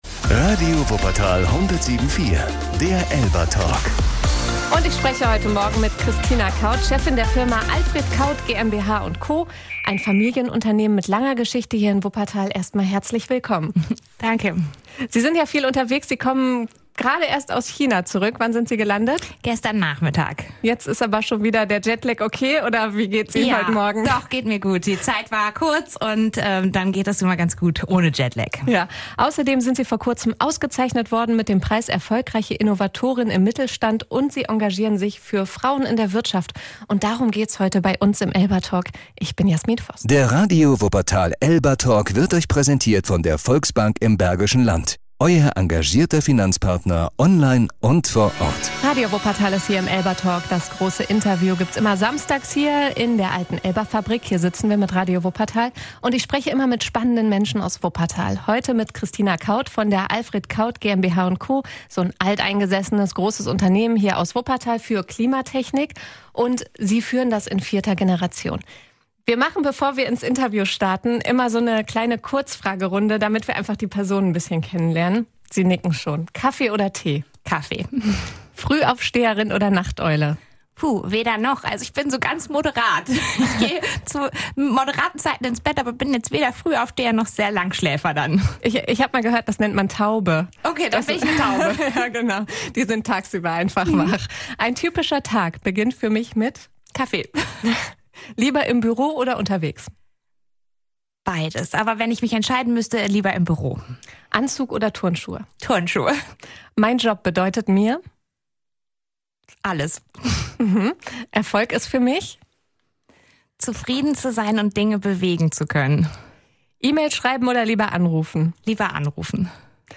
Hört euch hier das ganze Interview über Standort, Führungspositionen und Wuppertaler Geschichte an!